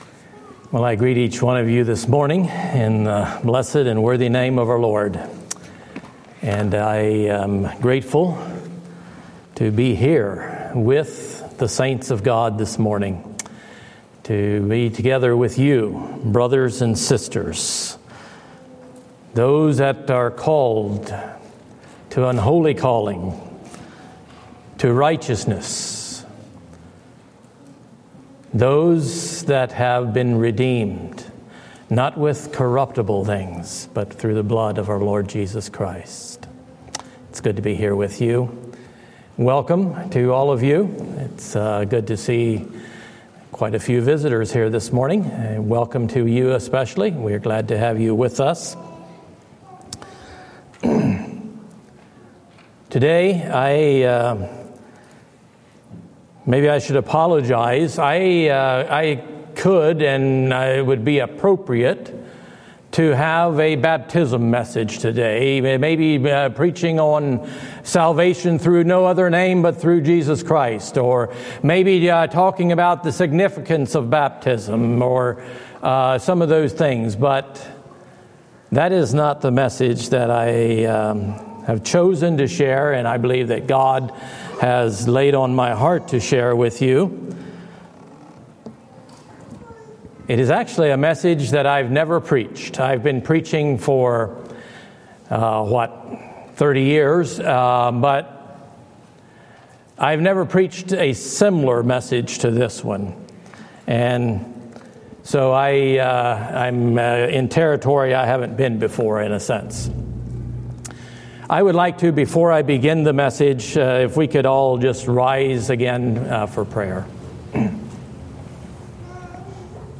Living Hope | Sermon